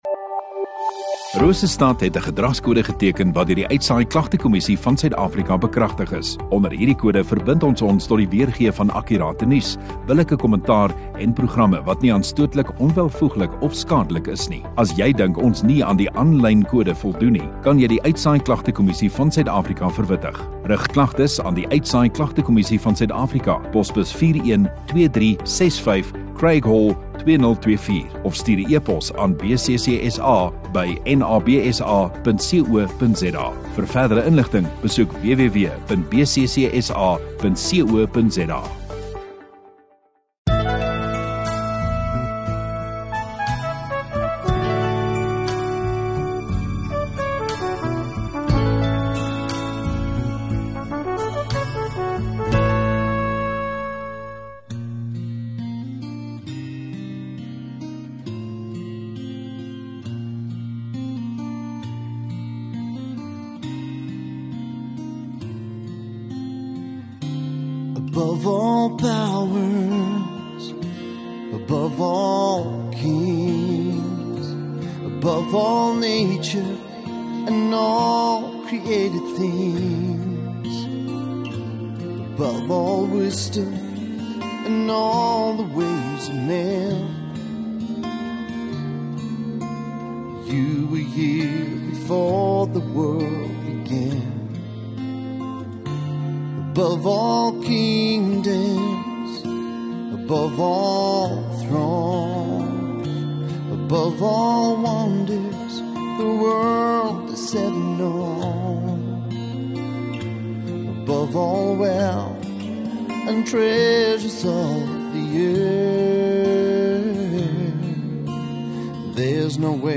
29 Jun Sondagaand Erediens